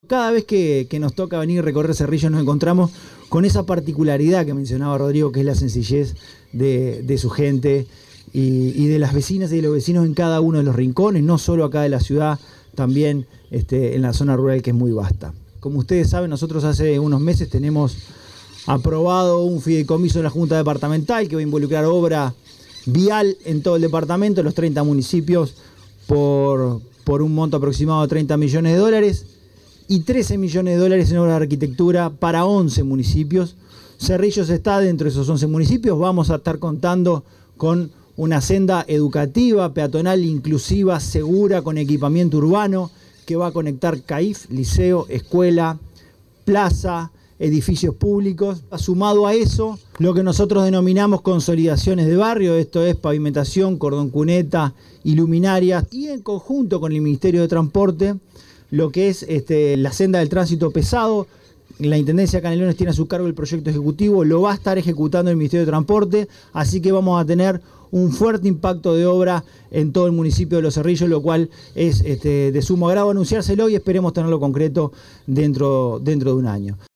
En presencia del Presidente de la República, Dr. Luis Lacalle Pou, el Secretario de Presidencia, Álvaro Delgado, el Secretario General de la Intendencia de Canelones, Dr. Esc. Francisco Legnani, la Pro Secretaria General, As. Soc. Silvana Nieves, el Alcalde del Municipio de Los Cerrillos, Prof. Rodrigo Roncio, integrantes de la Asociación Histórica de Los Cerrillos, autoridades nacionales, departamentales y locales, instituciones educativas, vecinas y vecinos, se realizó el acto conmemorativo del 126° aniversario de la ciudad de Los Cerrillos, en la plaza José Batlle y Ordóñez.